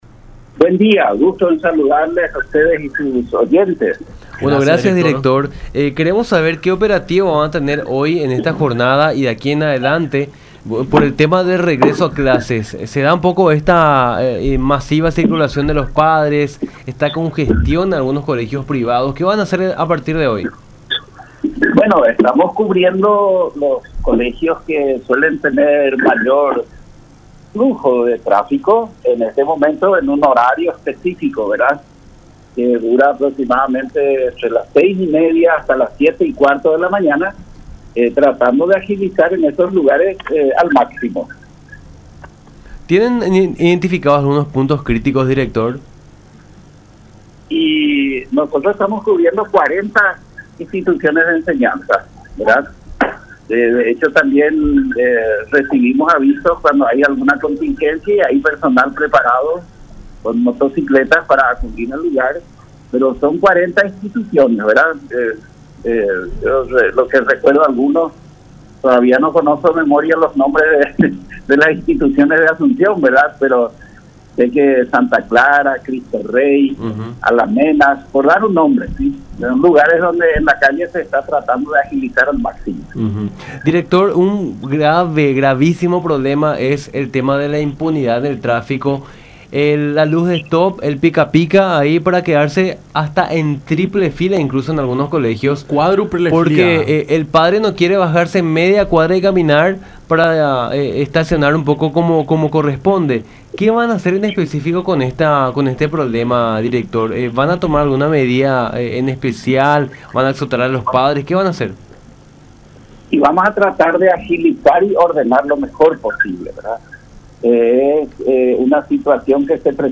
“Está prohibido quedarse de manera permanente, tendrán que bajar a sus chicos y seguir adelante. Se debe tardar solo 10 segundos en bajar a los alumnos”, afirmó el director de la PMT asuncena, Luis Christ Jacobs, en diálogo con Nuestra Mañana por La Unión.